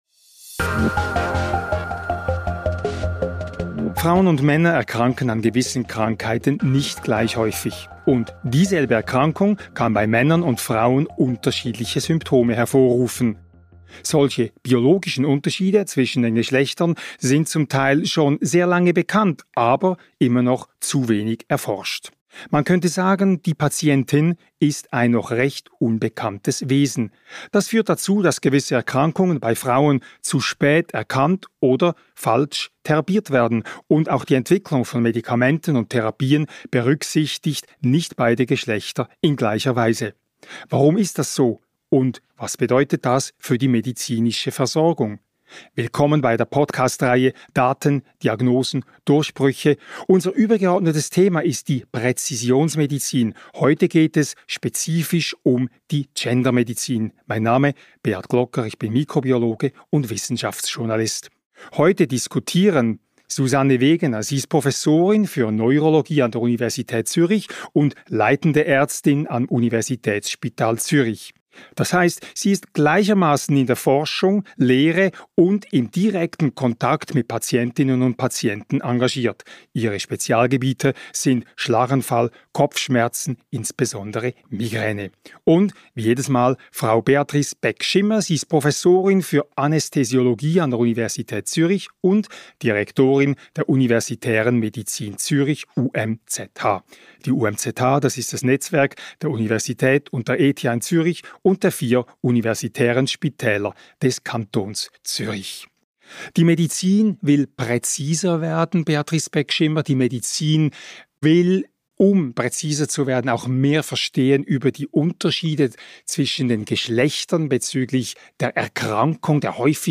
Ein Gespräch über Schlaganfälle und Migräne bei Männern und Frauen, über das bisher langsame Vorankommen in der Gendermedizin und darüber, weshalb selbst Präventionskampagnen neu gedacht werden müssen.